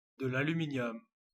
Ääntäminen
IPA : /ˌæl.(j)ʊˈmɪn.i.əm/